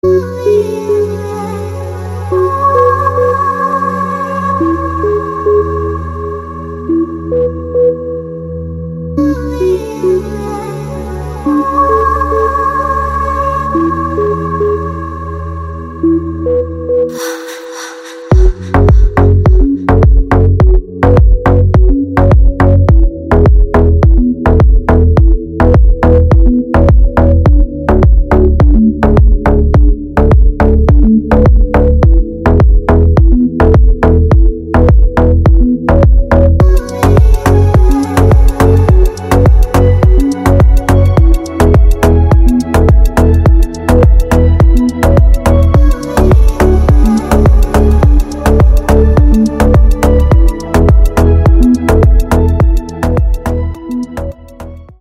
electronic
Deep House , нарастающие
Bass Boosted